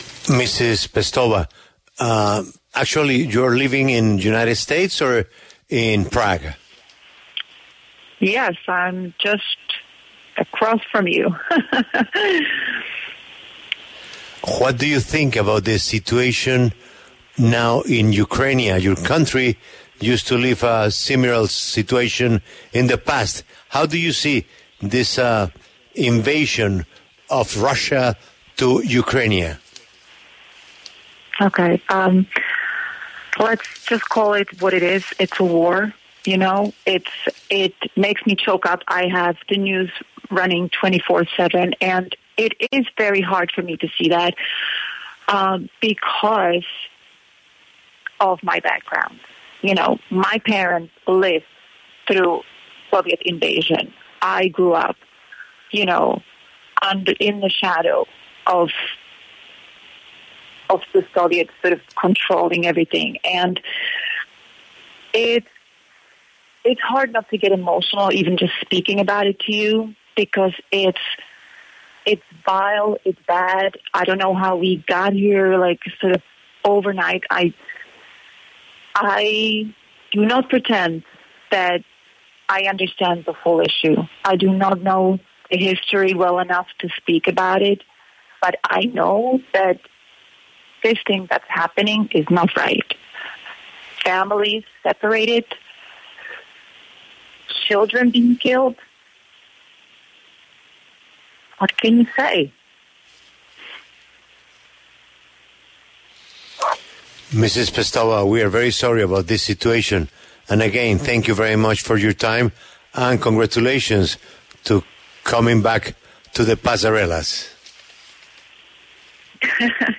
Daniela Pestova, modelo checa que a los 51 años vuelve a Victoria’s Secret, habló en La W sobre el conflicto en Ucrania por la invasión rusa.
En la conversación se conmovió por las imágenes que ha visto de familias ucranianas tratando de huir por los bombardeos.